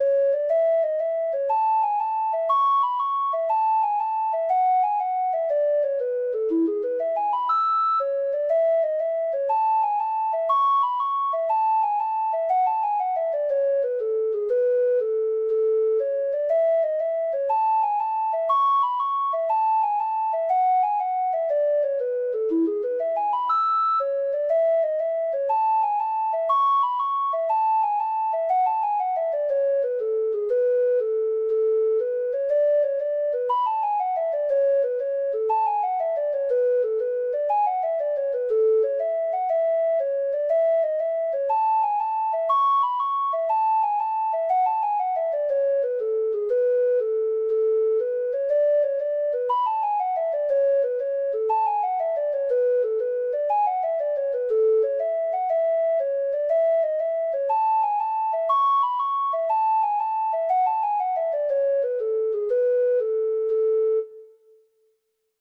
Traditional Music of unknown author.
Hornpipes
Irish